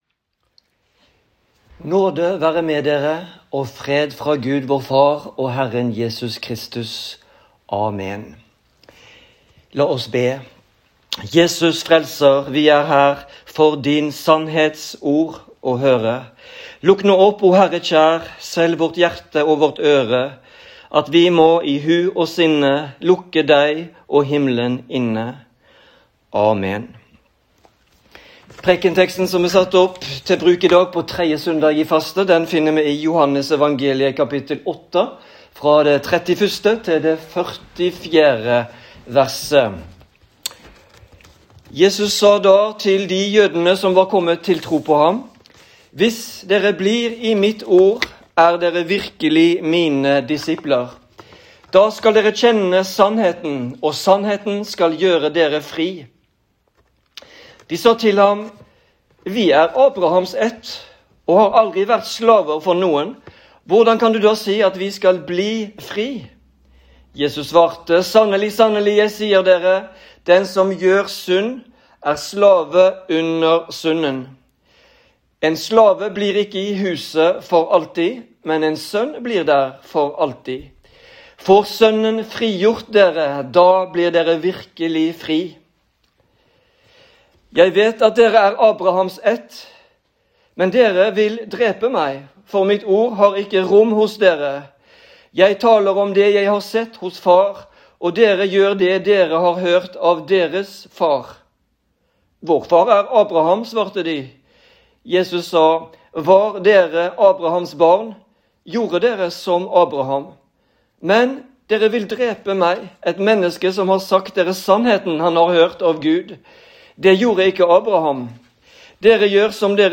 Preken på 3. søndag i faste